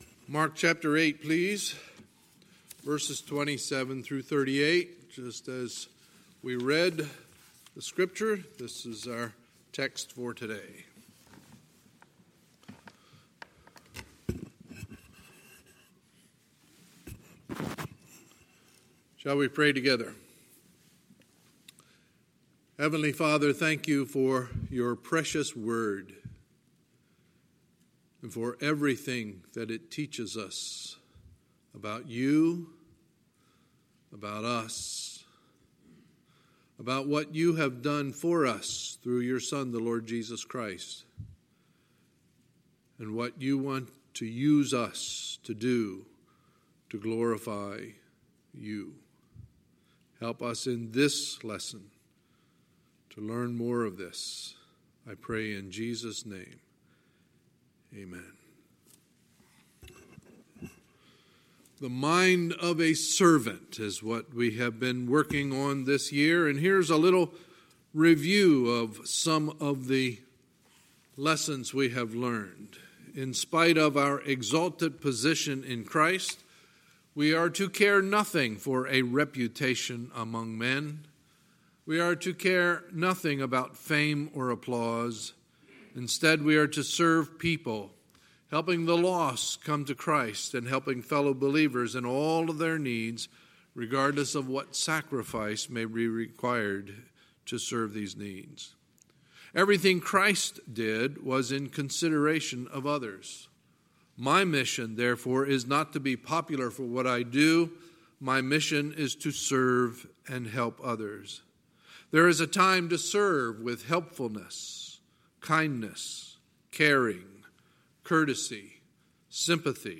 Sunday, August 4, 2019 – Sunday Morning Service